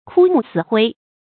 枯木死灰 注音： ㄎㄨ ㄇㄨˋ ㄙㄧˇ ㄏㄨㄟ 讀音讀法： 意思解釋： 死灰：燃燒后余下的冷灰。